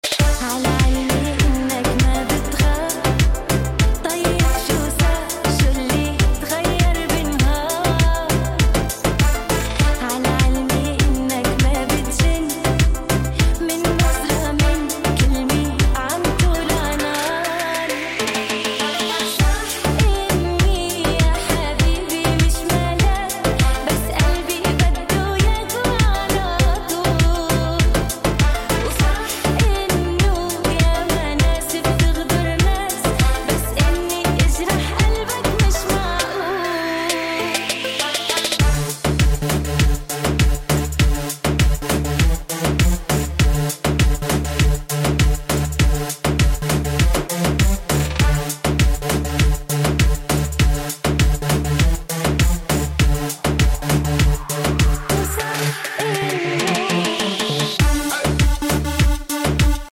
Remix جديد غير شكل